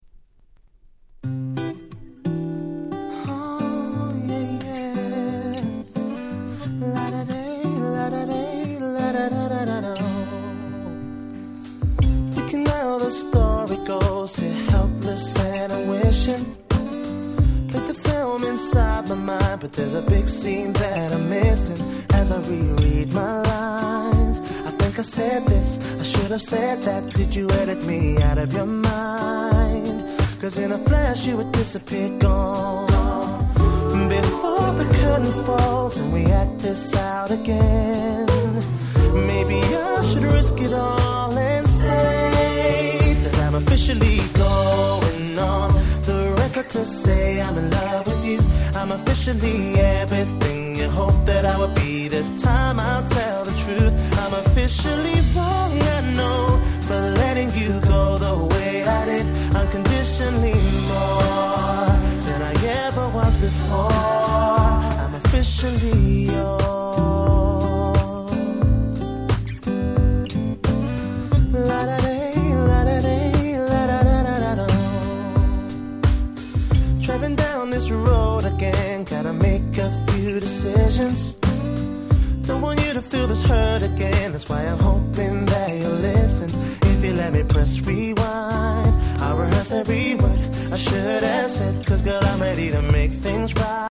08' Nice R&B !!
カップリングの2曲も前述のアルバムに収録されていた楽曲で美メロかつSmoothでバッチリ！